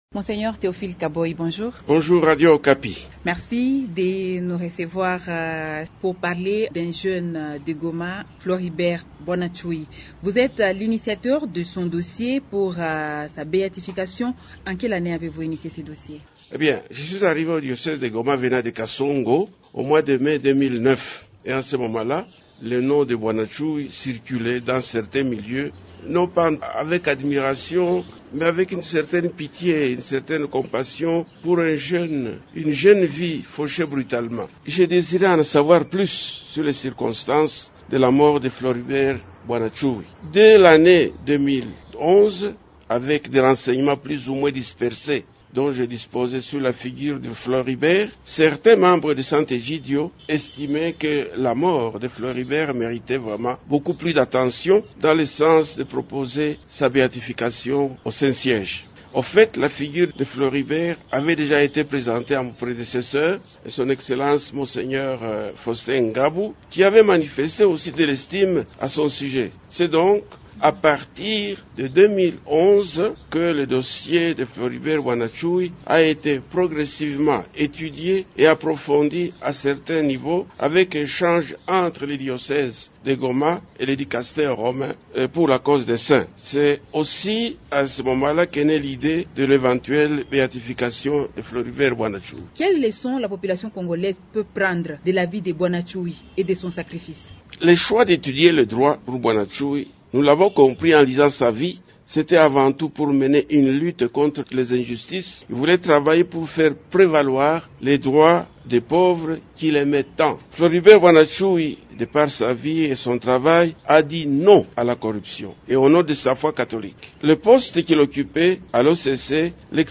« C’est un jeune qui a donné sa vie pour défendre la vérité et protéger des vies. L’Église se devait de faire connaître cet acte héroïque au monde entier », estime l'évêque dans un entretien